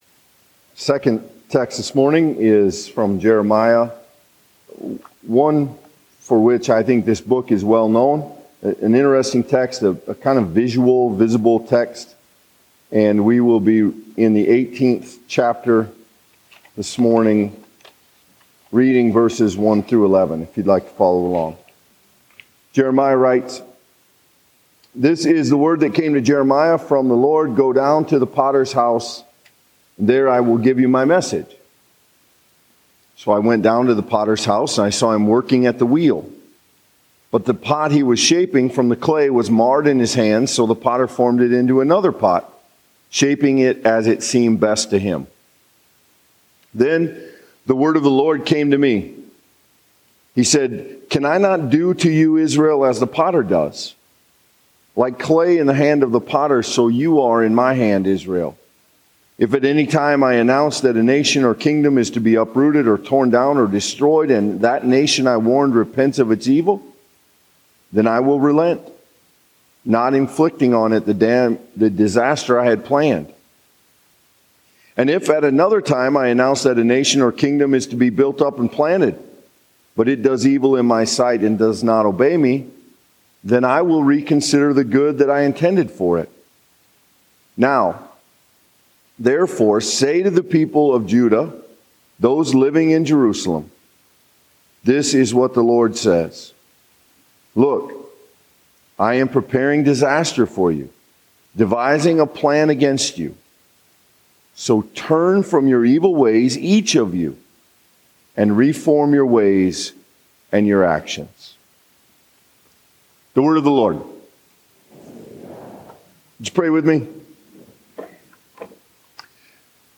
Sermons & Bulletins